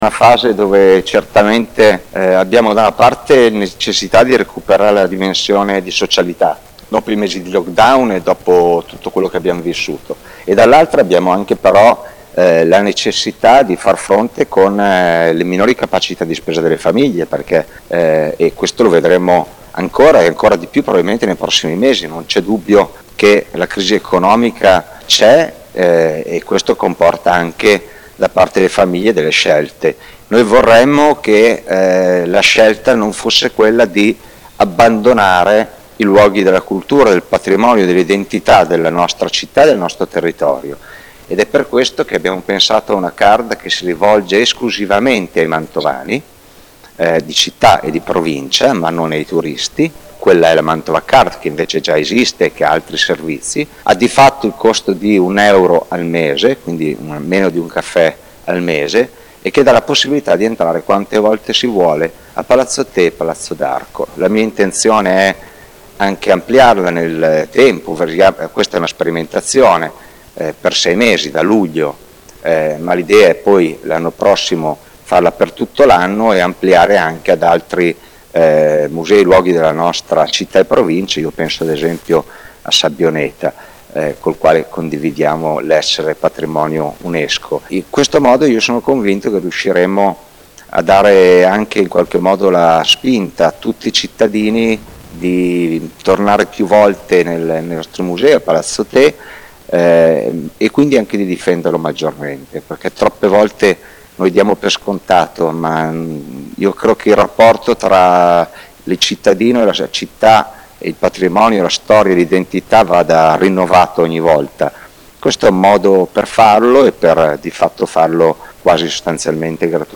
A commentare le iniziative al nostro microfono:
il sindaco di Mantova, Mattia Palazzi
Mattia-Palazzi-sindaco-di-Mantova.mp3